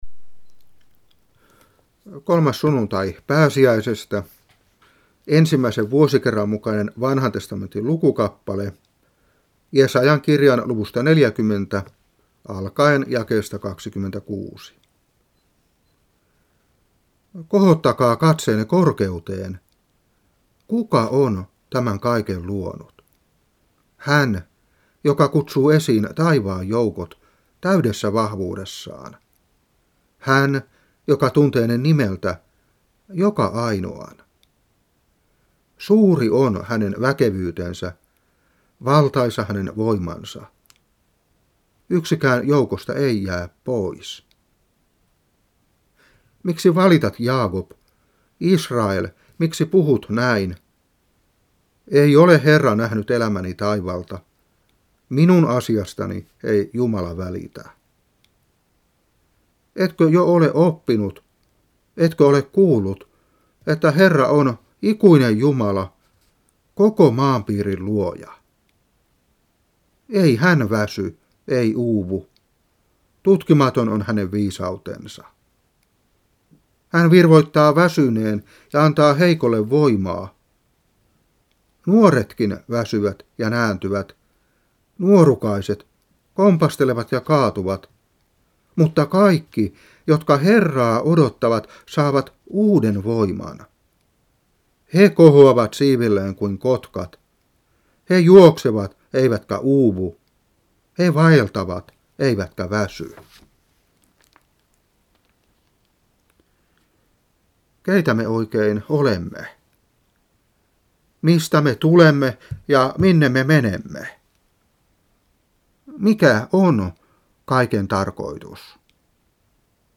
Saarna 1995-5.